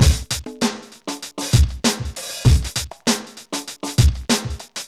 GROOVE 98.wav